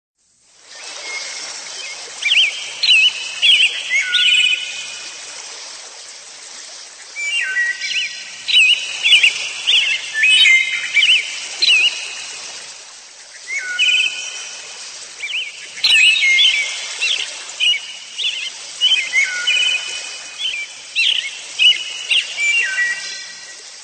Android, Naturljud